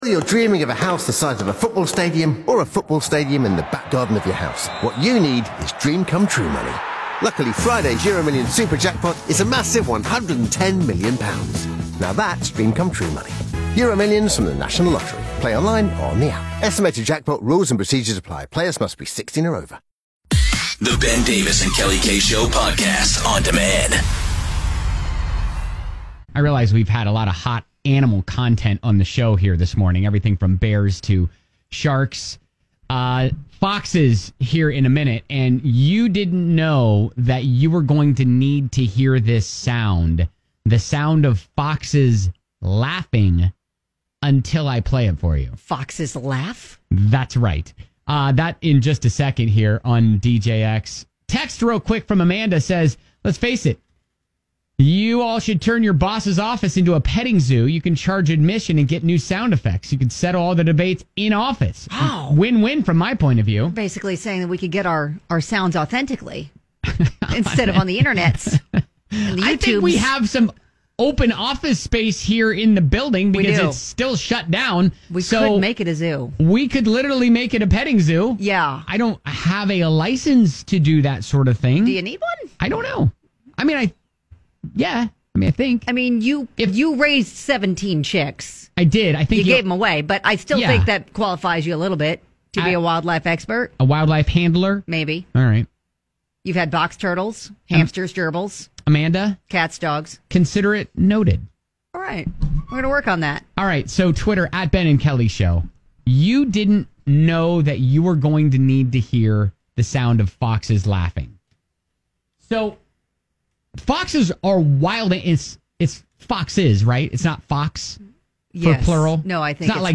You Need To Hear The Sound Of Foxes Laughing